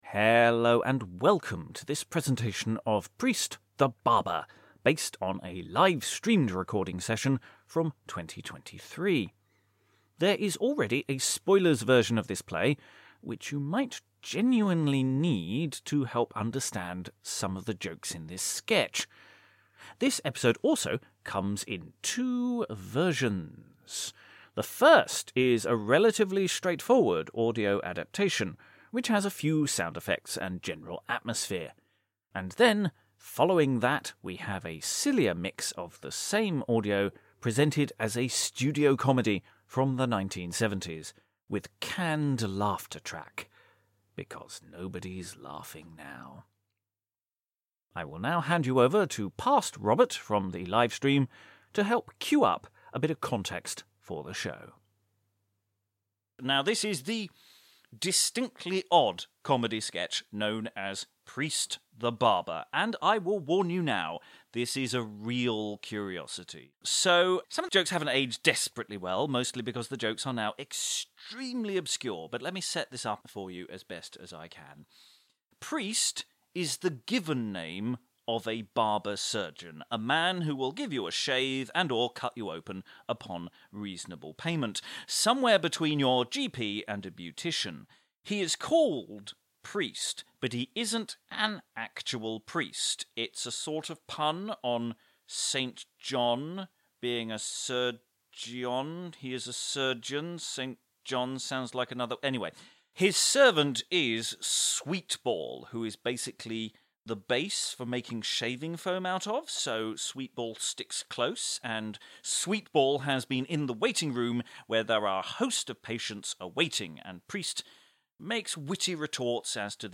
Preist the Barber by the Unknown (Full Cast Audio Adaptation)
This episode also comes in two full cast audio versions – the first is a relatively straightforward audio adaptation, which has a few sound effects, and then a sillier mix of the same presented as a studio comedy from the 1970’s with canned laughter track.
Edit one (standard edit) opens the episode, edit two (canned laughter) starts from 9mins 20sec Our patrons received this episode in January 2024 - approx.